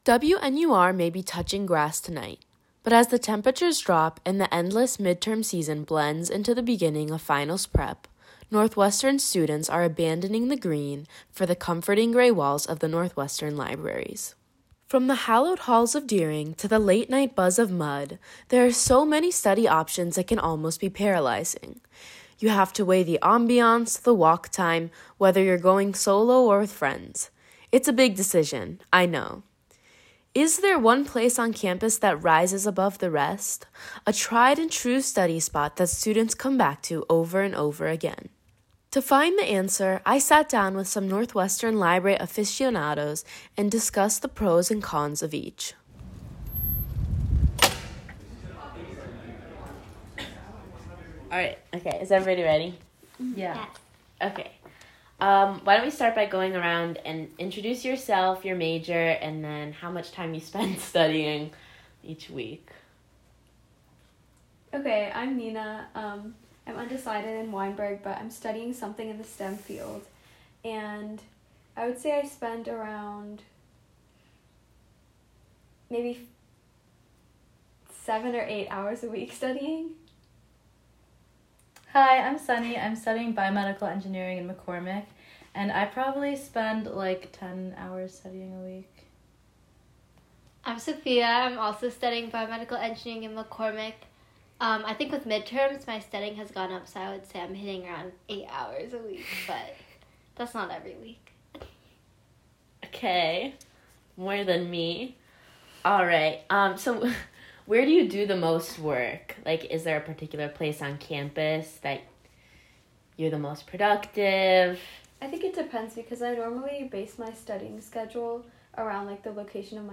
Battle of the Libraries: A Roundtable on the Best Campus Study Spots
This story originally aired as part of our WNUR News Touches Grass Special Broadcast.